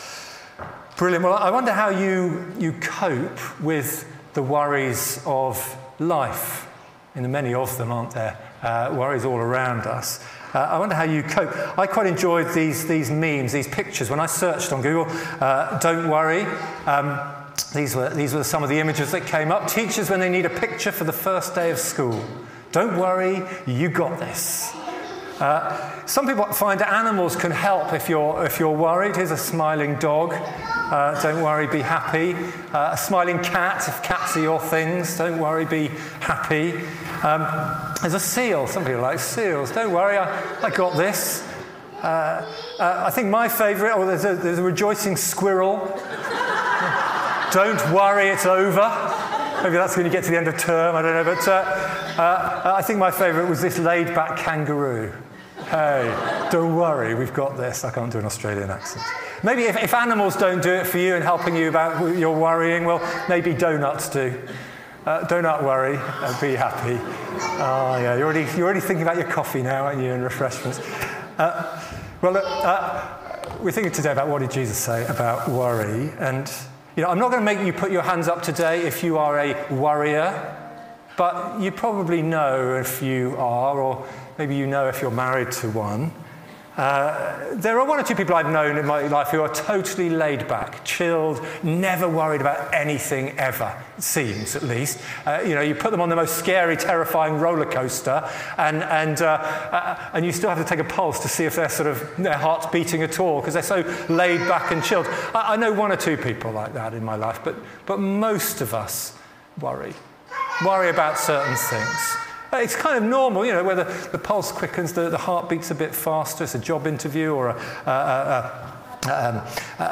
Sermon, Emmanuel Church, Coping, worries, life
Passage: Luke 12: 22-34 Service Type: Sunday Morning